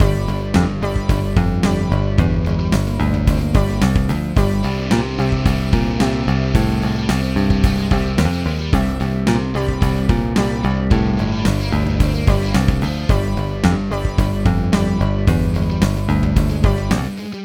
Lesson 2: Creating a Rock Song
LI: We are learning to create a rock song using chords..
lesson-2-example-rock.wav